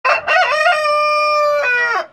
01-kakas.mp3